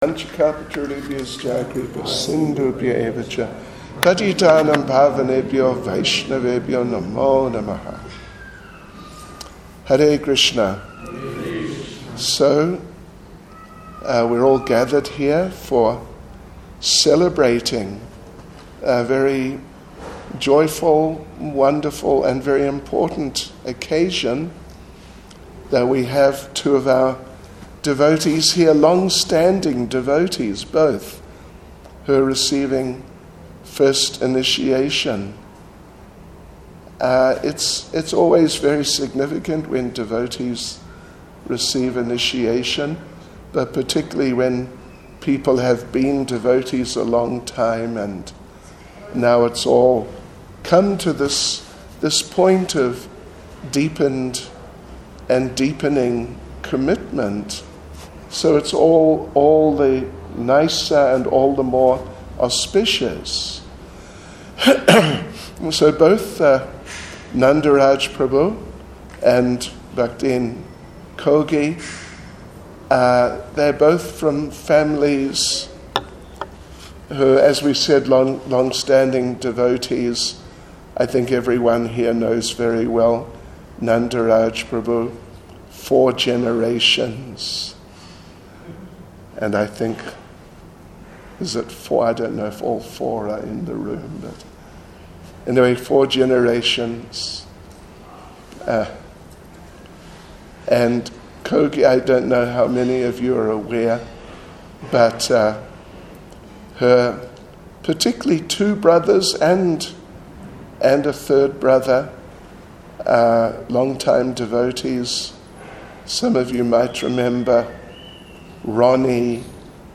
3-Dec-16-Initiation-Ceremony-Speech-ISKCON-Lenasia.mp3